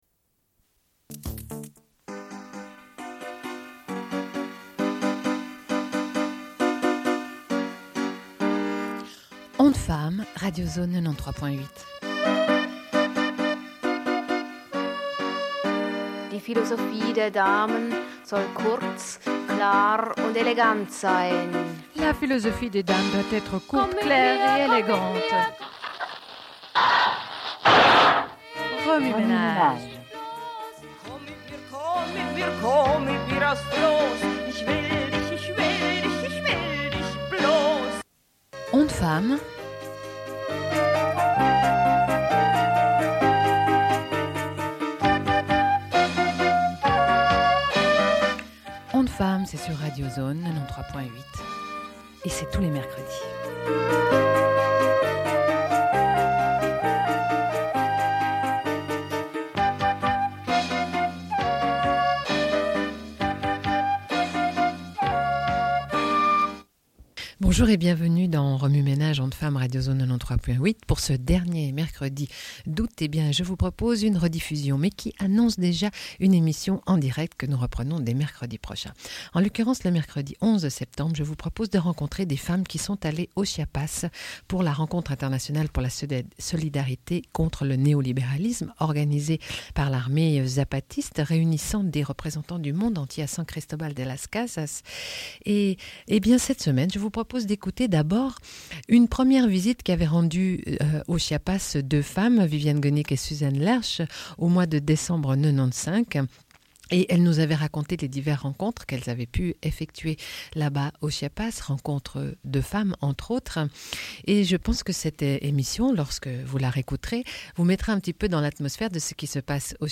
Diffusion d'enregistrements pris sur place par les deux femmes qui ont rencontré d'autres femmes sur place.
Une cassette audio, face A